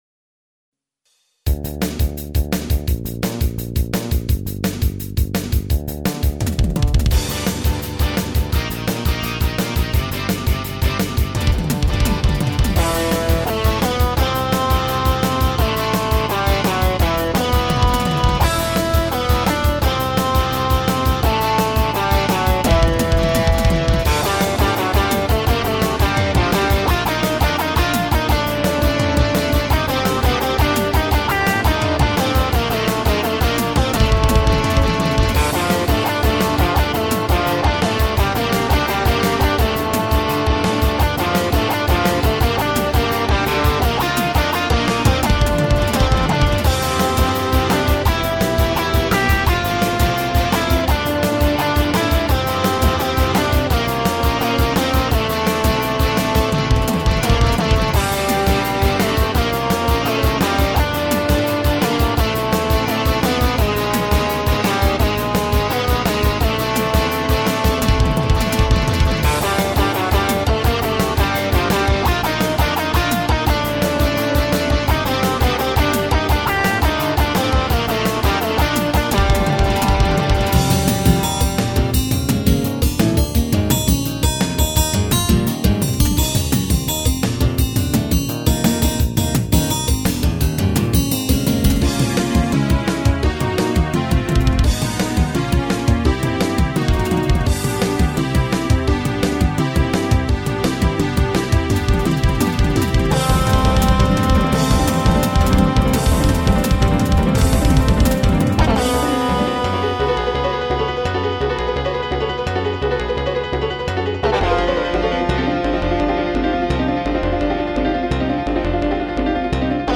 PROGRESSIVE ROCK MUSIC